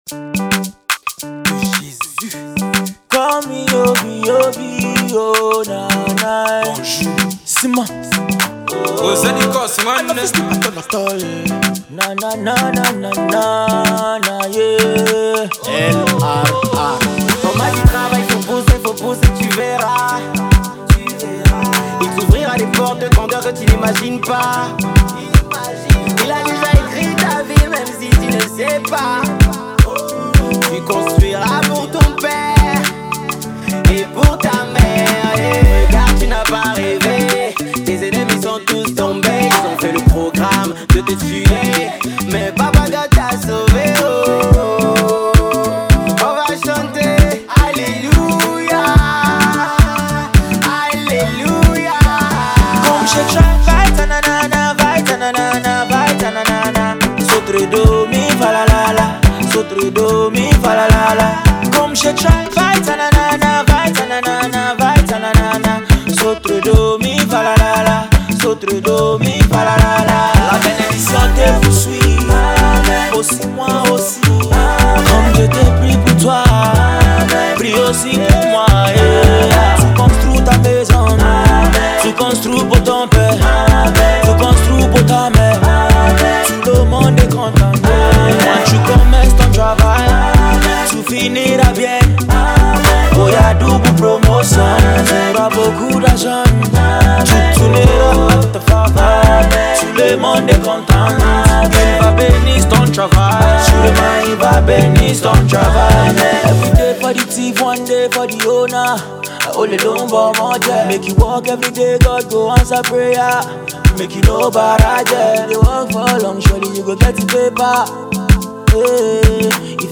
French Remix